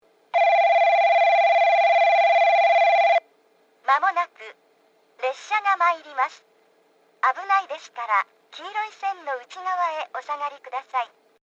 接近放送　女声（おれんじ鉄道ホーム）
おれんじ鉄道ホームは九州カンノ型Aです。
スピーカーはJRホームがJVCラインアレイやカンノボックス型、おれんじ鉄道はTOAラッパ型です。